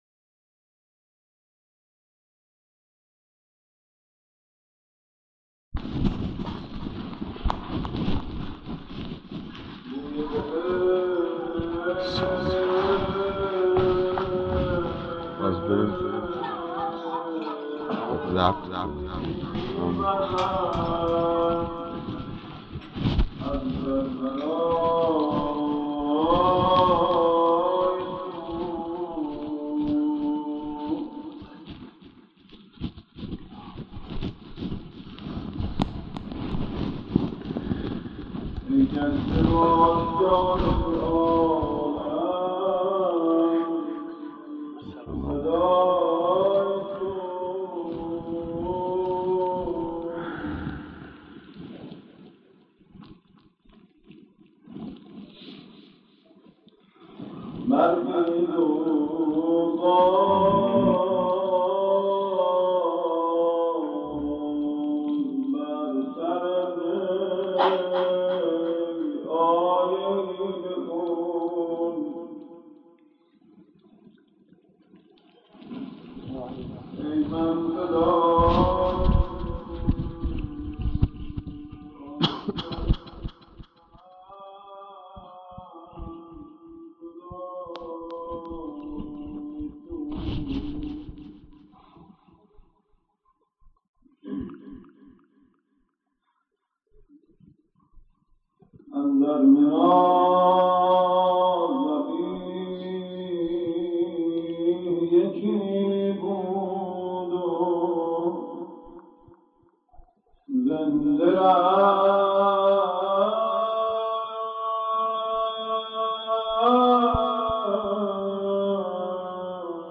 روضه خوانی در 22 صفر سال 1411 هـ.ق